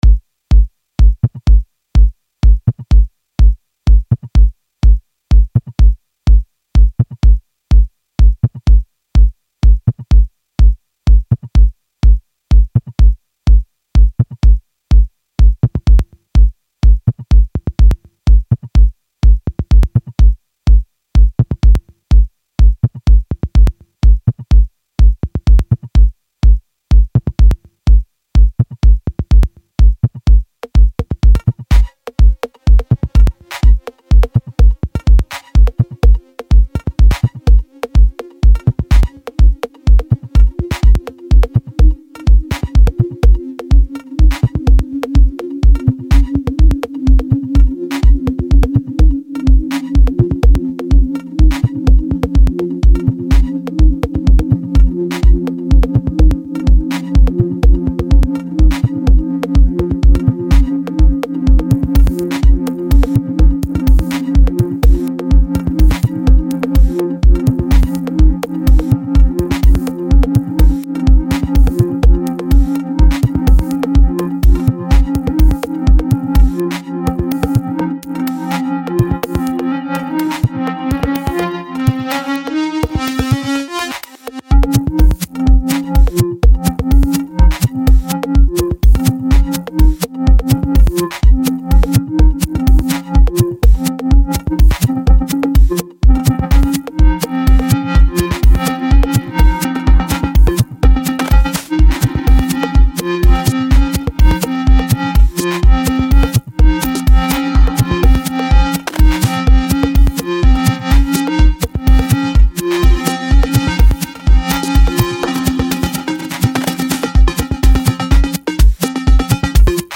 техно
tehno.mp3